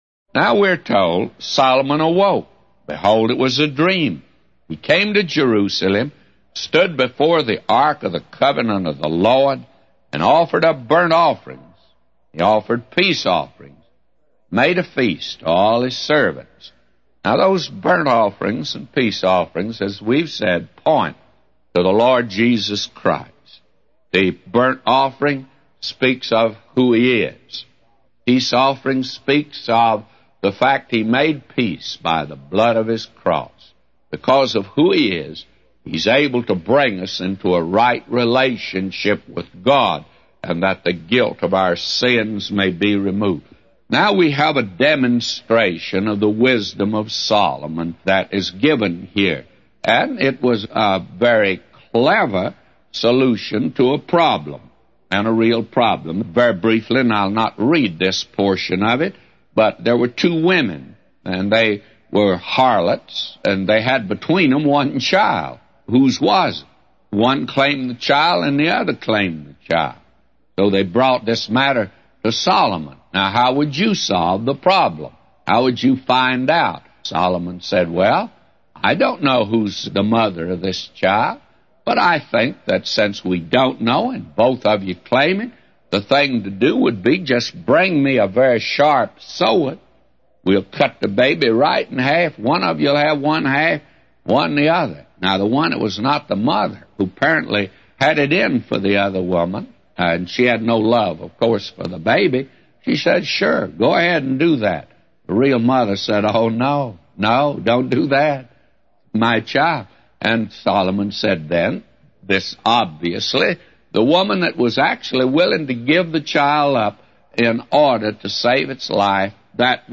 A Commentary By J Vernon MCgee For 1 Kings 3:15-999